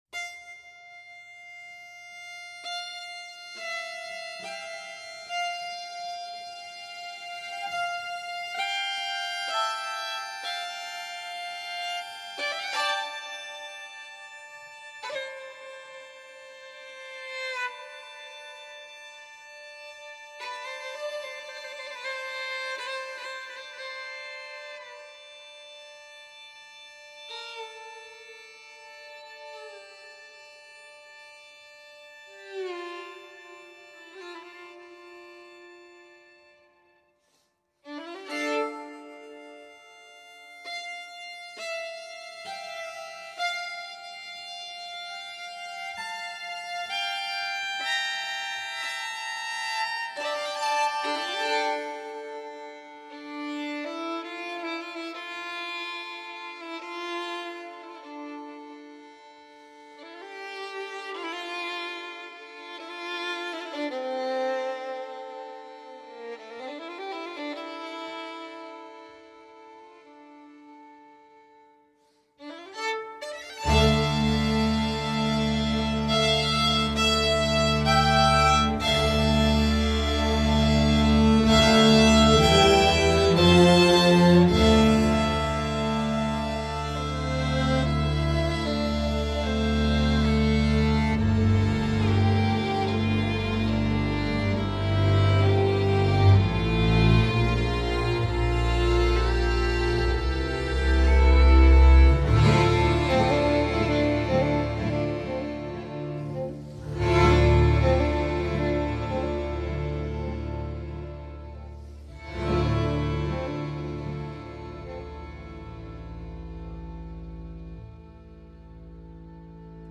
کوارتت زهی